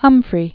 (hŭmfrē, hŭmp-), Doris 1895-1958.